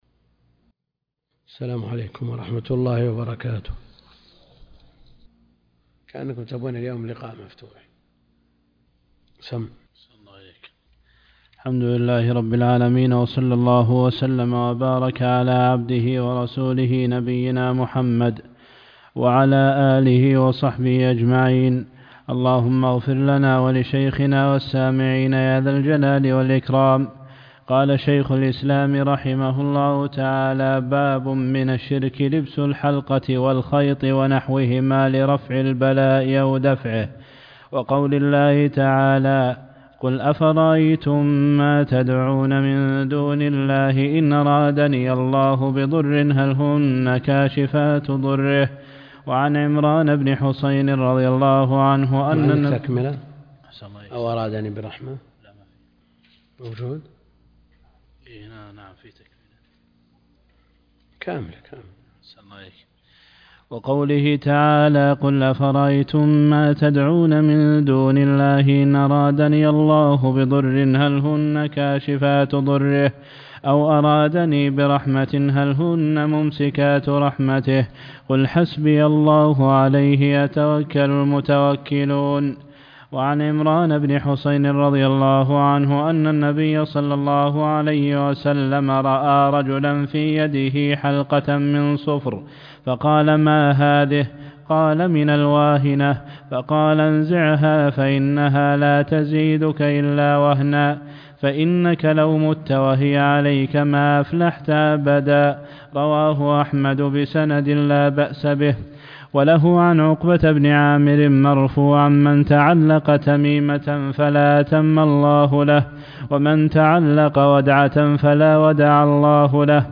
تفاصيل المادة عنوان المادة الدرس (9) شرح كتاب التوحيد تاريخ التحميل الأربعاء 4 يناير 2023 مـ حجم المادة 35.04 ميجا بايت عدد الزيارات 284 زيارة عدد مرات الحفظ 153 مرة إستماع المادة حفظ المادة اضف تعليقك أرسل لصديق